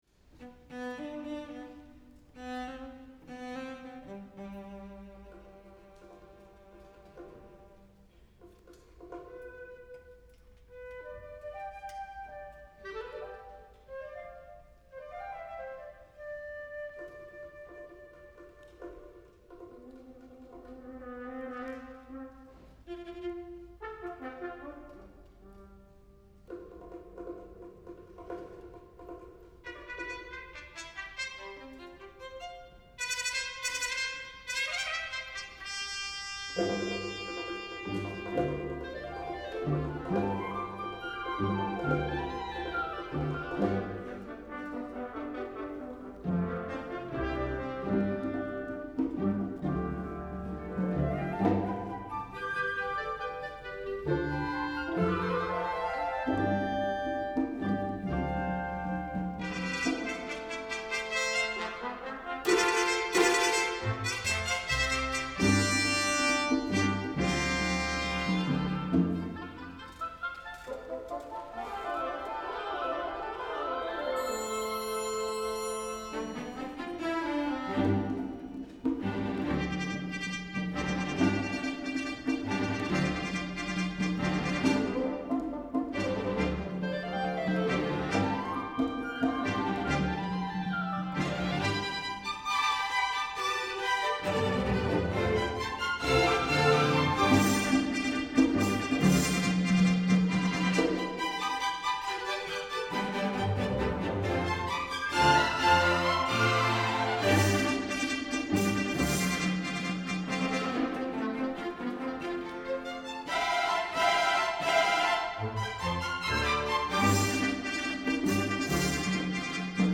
for Orchestra (1990)
an orchestral overture